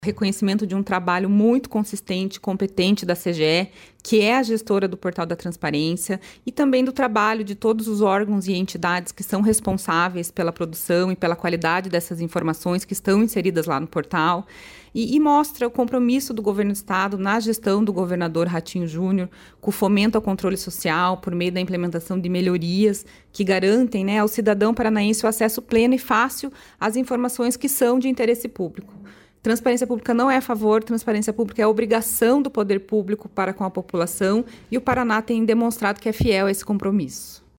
Sonora da controladora-geral do Estado, Louise da Costa e Silva, sobre a conquista do selo Diamante em Transparência Pública